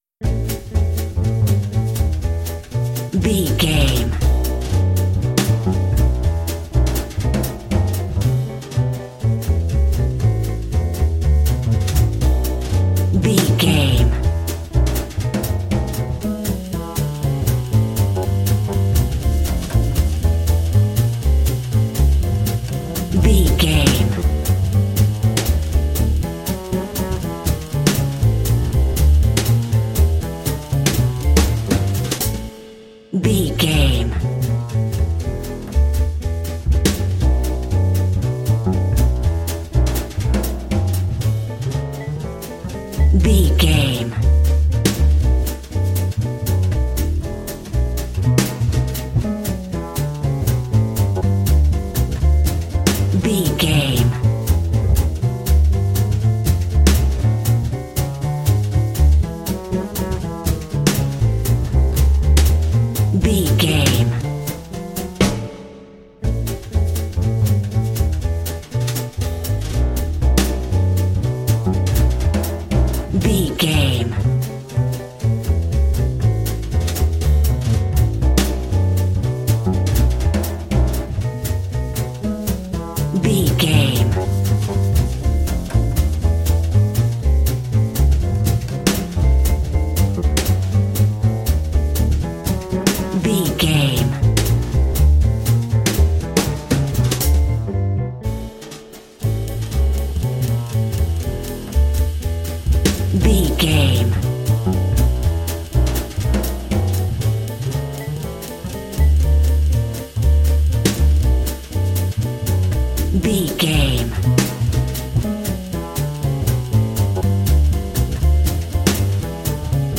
Ionian/Major
Fast
energetic
driving
groovy
lively
electric guitar
drums
double bass
bebop swing
jazz